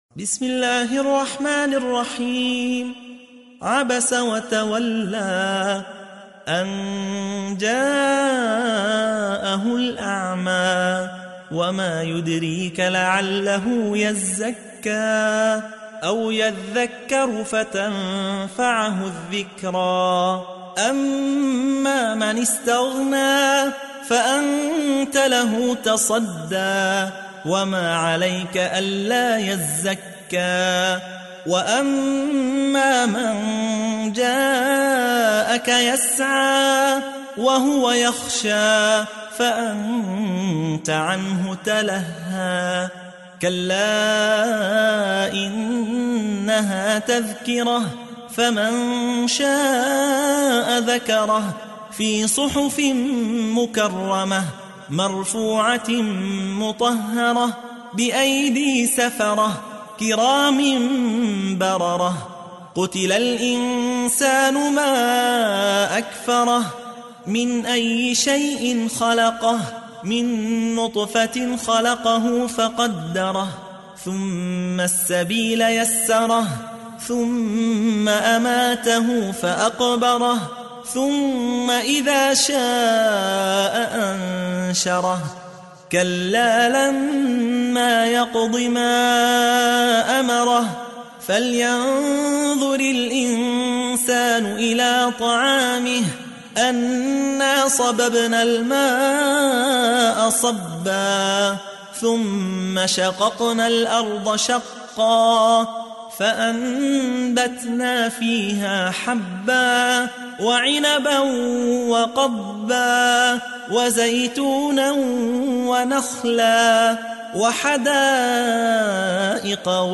تحميل : 80. سورة عبس / القارئ يحيى حوا / القرآن الكريم / موقع يا حسين